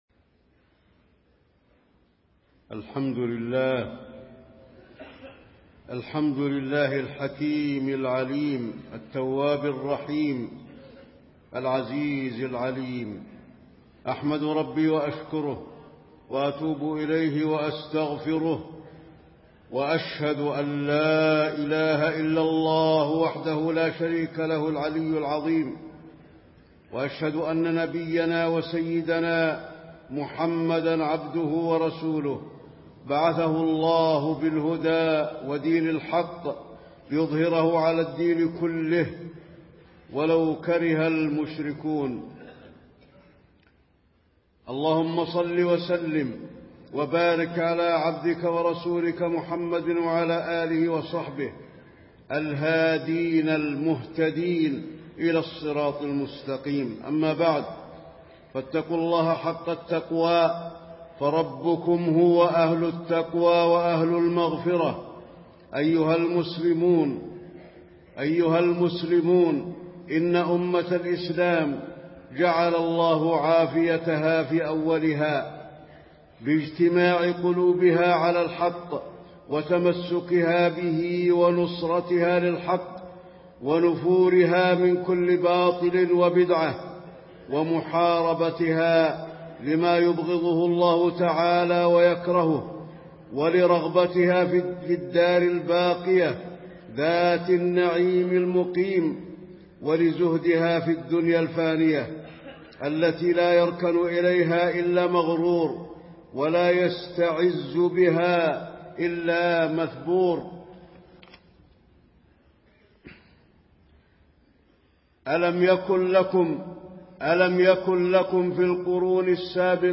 تاريخ النشر ٢٠ ذو الحجة ١٤٣٤ هـ المكان: المسجد النبوي الشيخ: فضيلة الشيخ د. علي بن عبدالرحمن الحذيفي فضيلة الشيخ د. علي بن عبدالرحمن الحذيفي فضل الاجتماع وخطر الفرقة